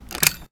weapon_foley_drop_02.wav